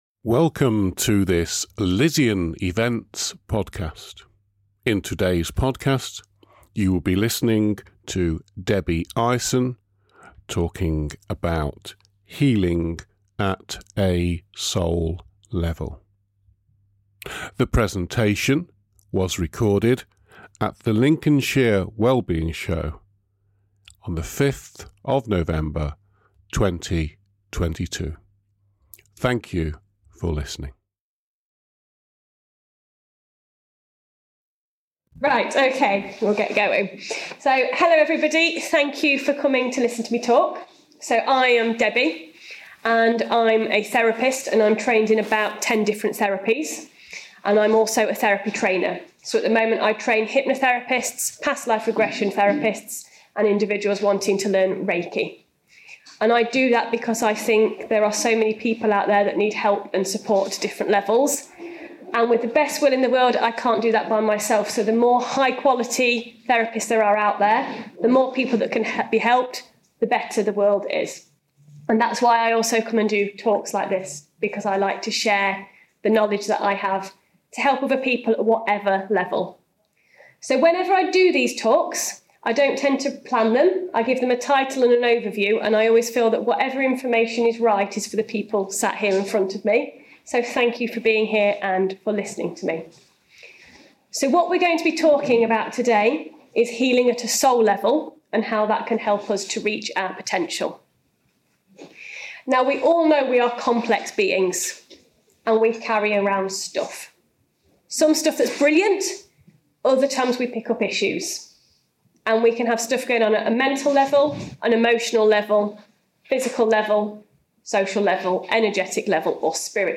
During the Lincolnshire Well Being Show, we recorded some of the talk presentations.